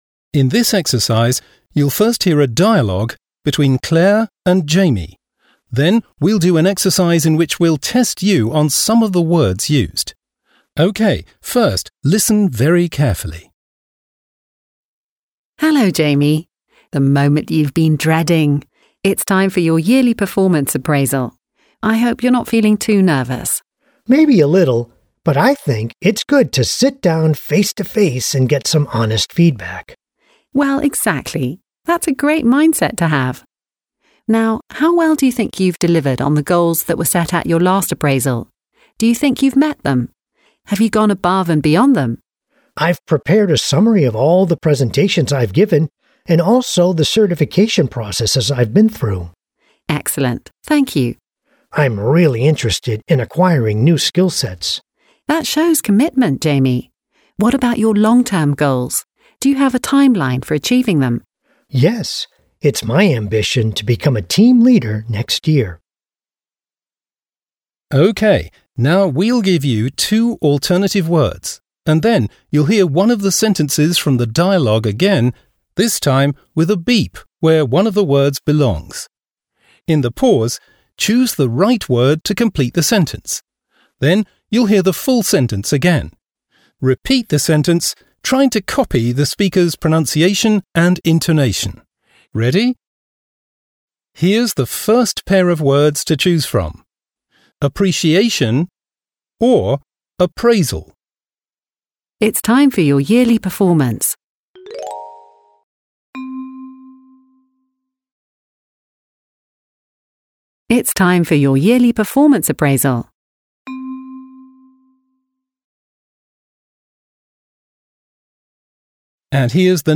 Audio-Übung
Audio-Trainer